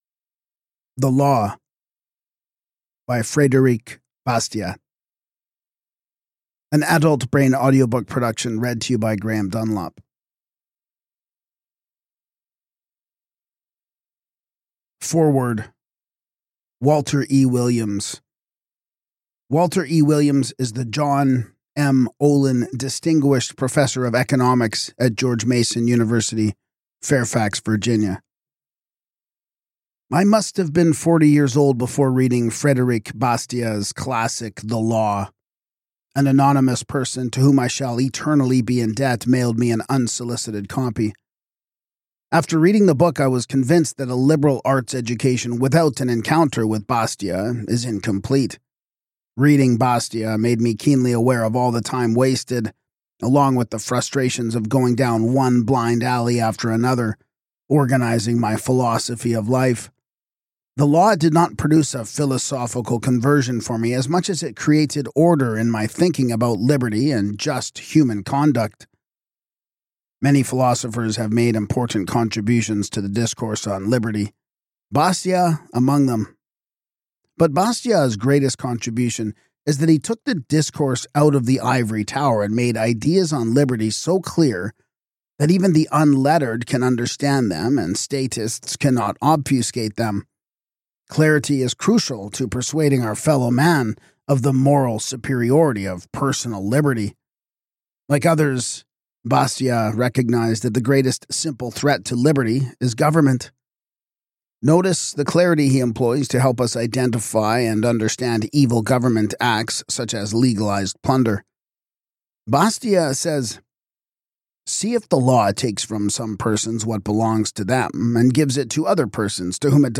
Whether you are new to political philosophy or a longtime student of liberty, this audiobook offers sharp insight into the principles of justice, government power, and individual rights.